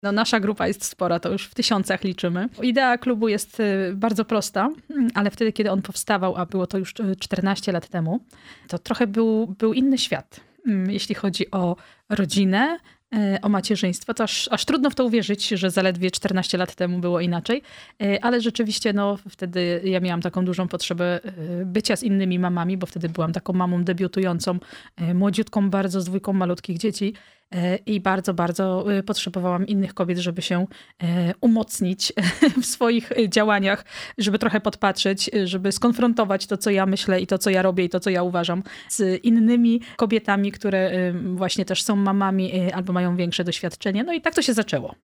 W studiu: